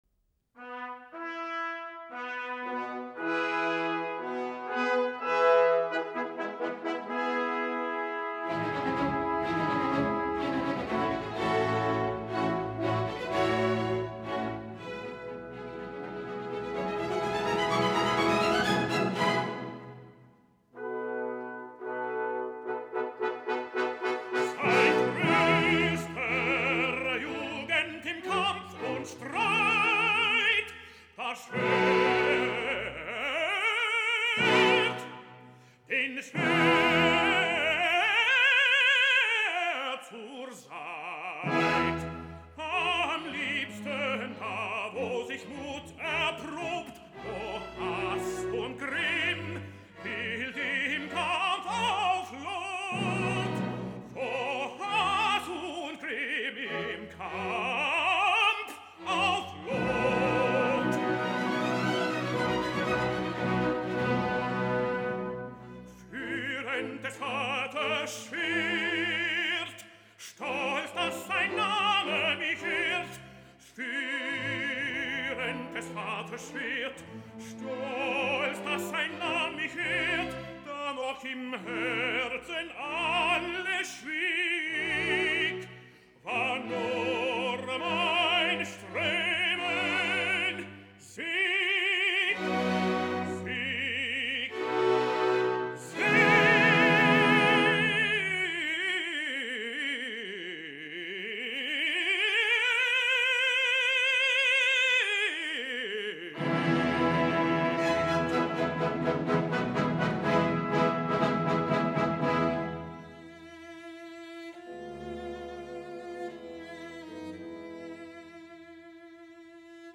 Ja ho sé que les comparacions sempre son odioses i que el que us posaré ara és una gravació d’estudi i Vogt ho canta en directa, però és clar que sent una gravació en diferit de France Musique, ja sabeu que no és garantia de res.
Així doncs, tenint en compte tot el que cal tenir en compte, us deixo a Ben Heppner, ara que passa per hores molt baixes, amb un dels moments més àlgids de la seva carrera discogràfica.
No és tan sols el gruix, el cos o el tremp de la veu, és que Heppner em sedueix i emociona amb un cant valent i molt viril, salvant tots els paranys  de l’endimoniada cabaletta amb exultant suficiència.